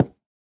Minecraft Version Minecraft Version latest Latest Release | Latest Snapshot latest / assets / minecraft / sounds / block / cherry_wood / break1.ogg Compare With Compare With Latest Release | Latest Snapshot
break1.ogg